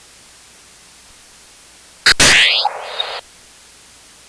Si a partir de este zoom nos movemos con las flechas del programa hacia la parte inferior derecha obtendremos el sonido correspondiente a este lado del planeta. Al principio tendremos un sonido fuerte correspondiente al cuerpo de Saturno, posteriormente éste desaparecerá pero sigue habiendo sonido debido a la presencia de dos satélites que, como se puede observar,  rodean Saturno.